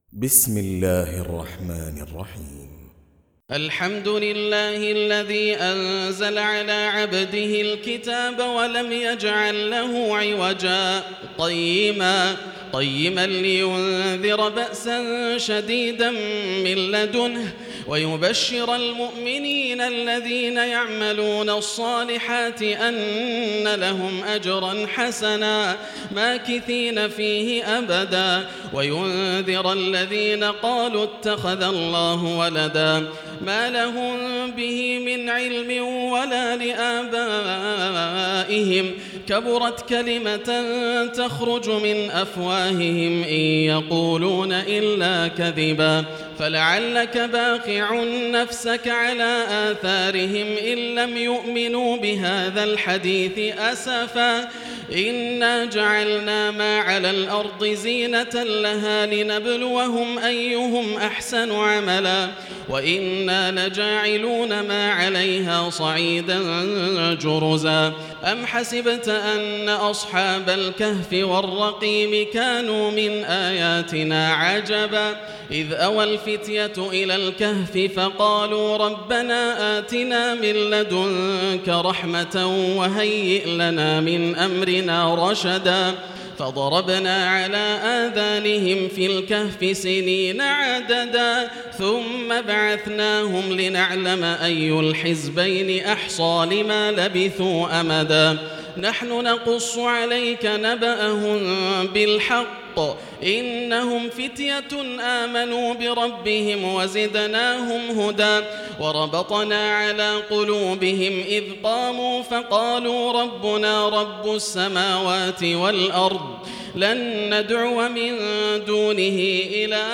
سورة الكهف > مصحف تراويح الحرم المكي عام 1440هـ > المصحف - تلاوات الحرمين